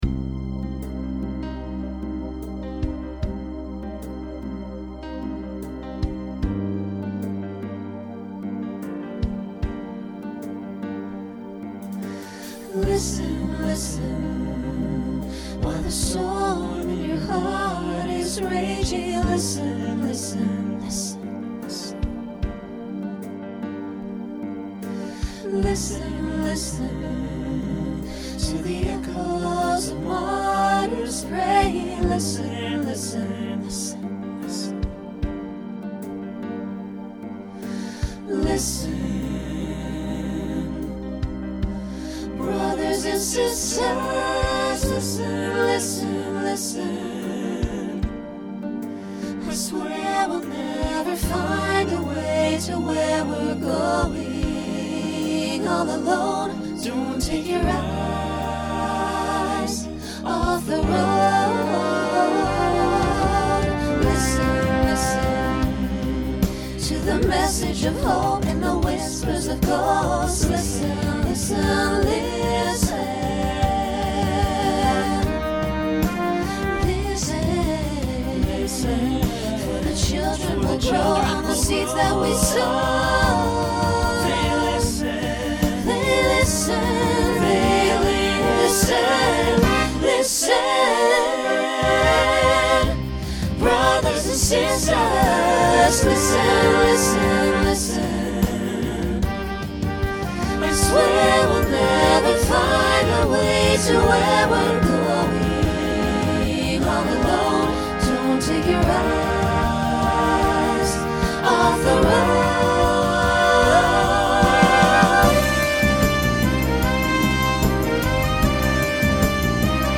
This is designed as a 2nd Number and Ballad in one.
Genre Pop/Dance
Ballad Voicing SATB